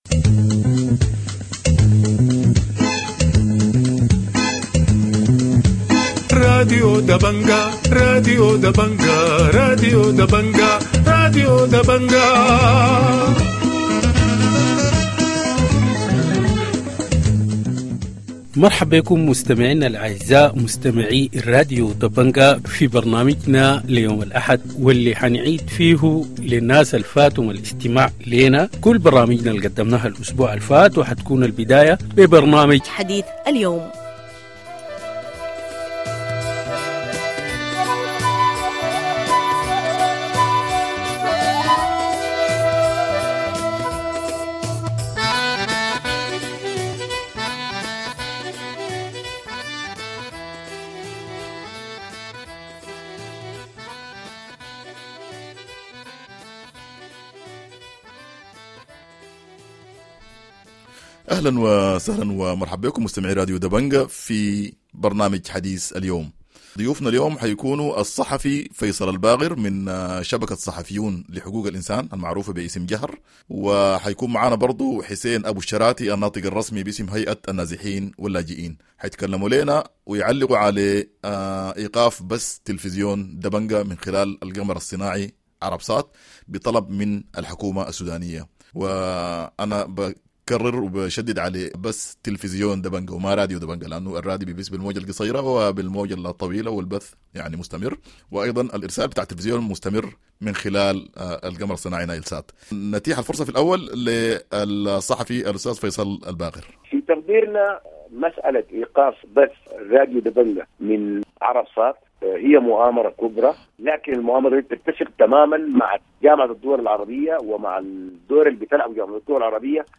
Morning news I 26 July - Dabanga Radio TV Online
news review weekend